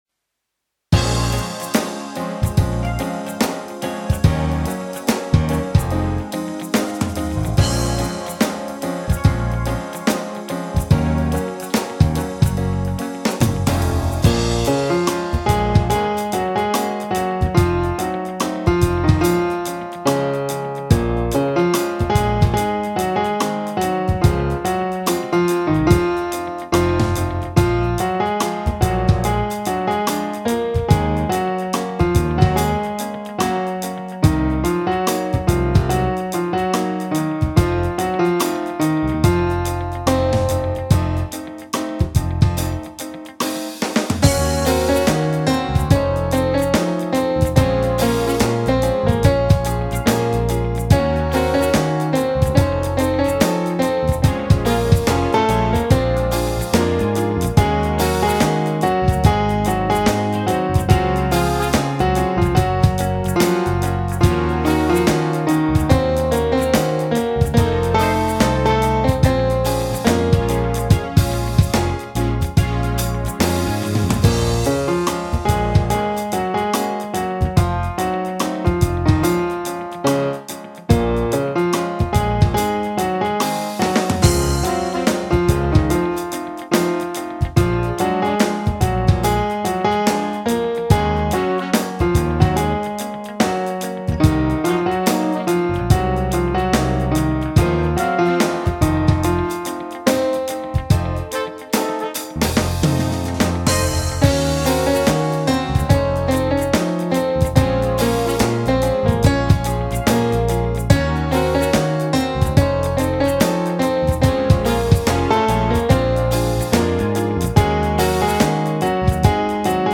ДОНЬКА УКРАЇНИ-ФЗ МЕЛОДIЄЮ 2.mp3